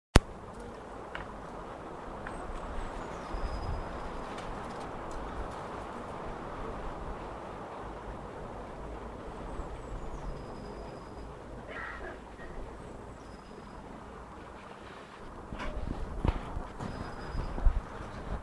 Anyone know what bird this is?